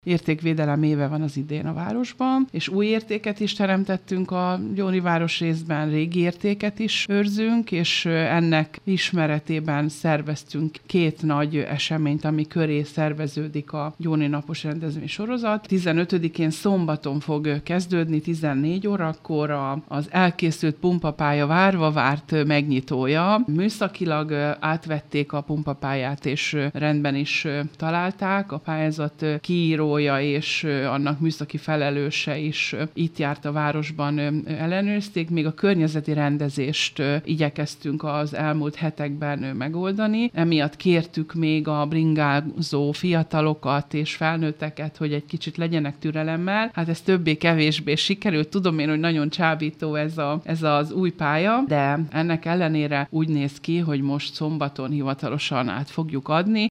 Ugyanakkor értékteremtés is történt, hiszen a Gyóni Napok nyitórendezvénye a pumpapálya átadása lesz. Pálinkásné Balázs Tünde alpolgármestert hallják.